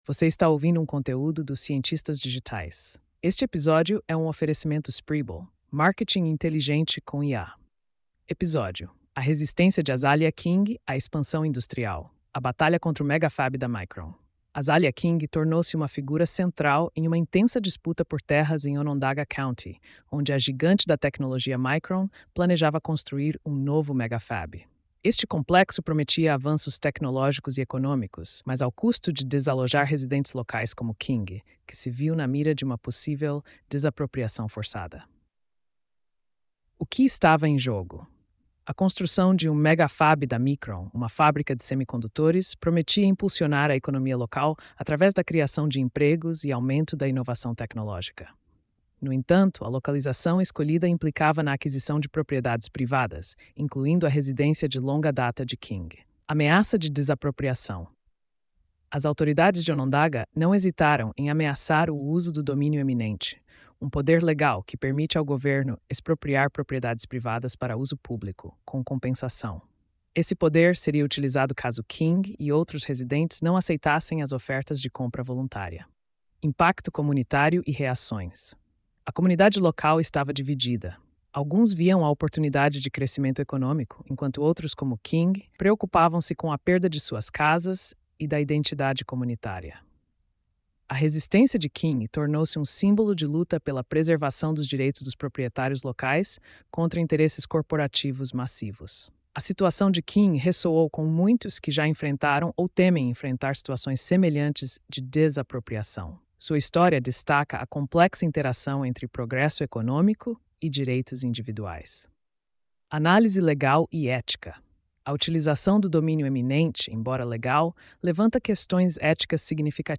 post-4555-tts.mp3